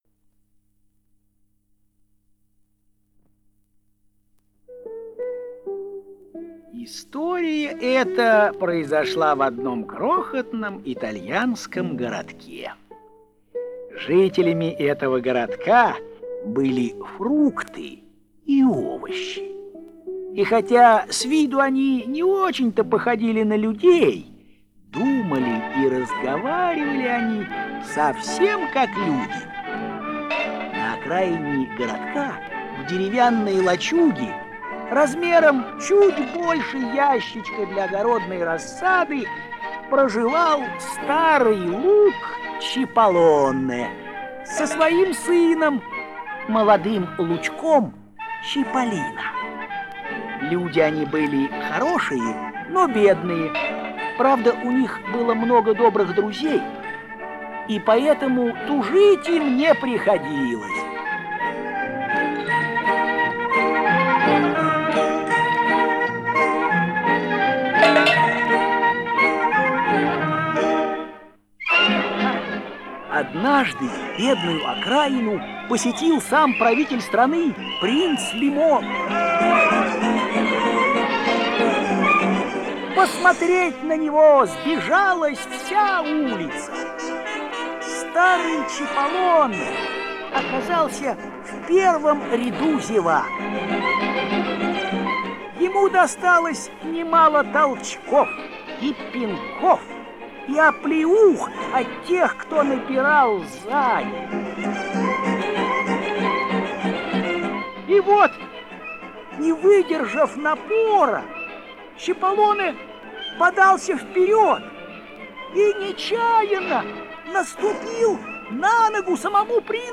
Что-то я подзабыл про оцифровки, надо же выполнять план.
Сама плёнка очень сильно пожёвана (особенно под конец), плюс местами затёрта чьими-то кривыми ручонками, так что за качество извиняюсь.